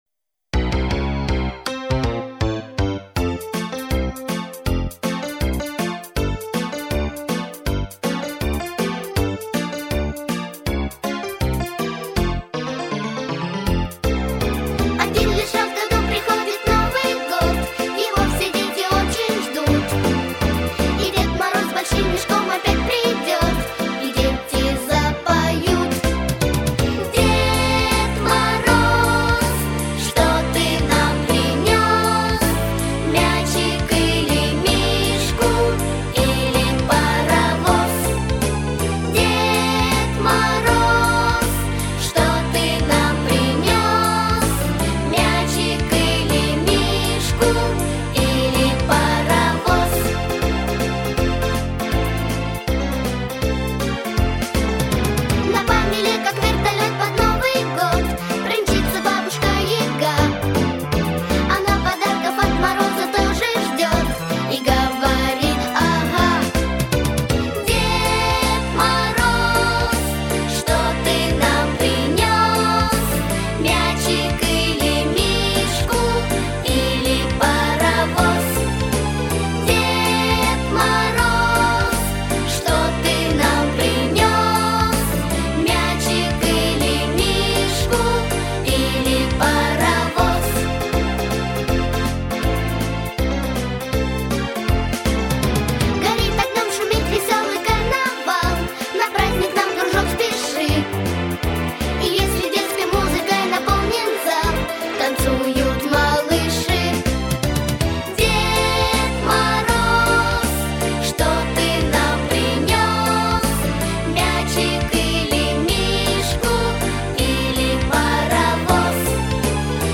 для детского сада